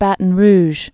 (bătn rzh)